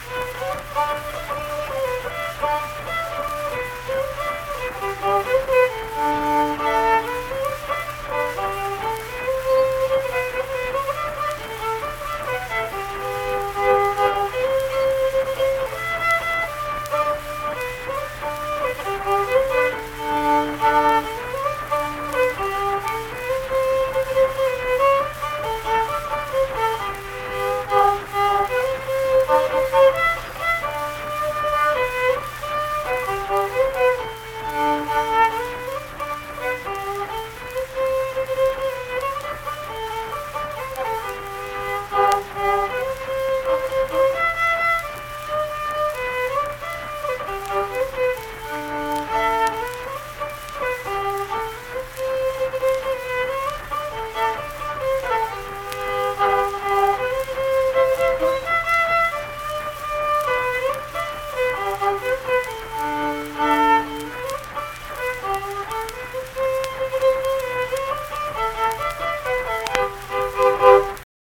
Unaccompanied fiddle music performance
Verse-refrain 1(2).
Instrumental Music
Fiddle
Harrison County (W. Va.)